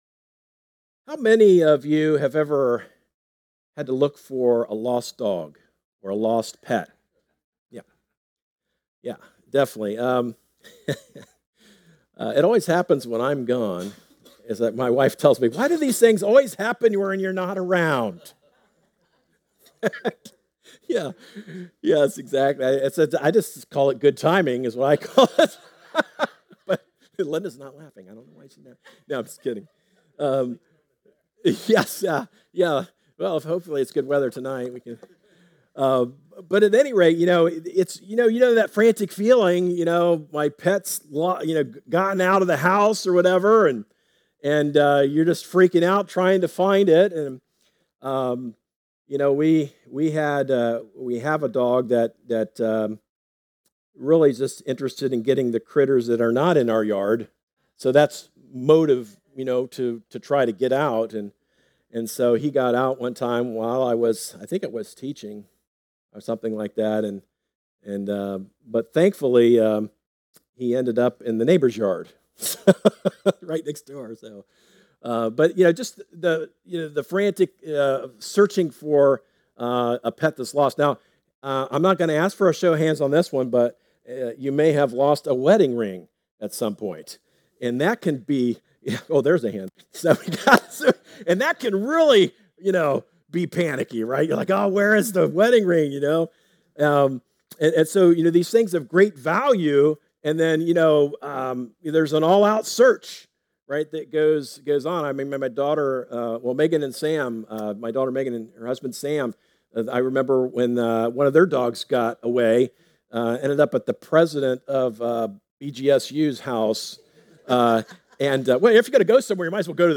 Our audio sermon podcast is available on most podcasting services including Spotify, Apple Podcasts, Stitcher, Google Podcasts and more!